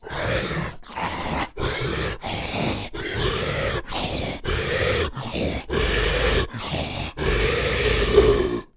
super_zombie_eating_1.wav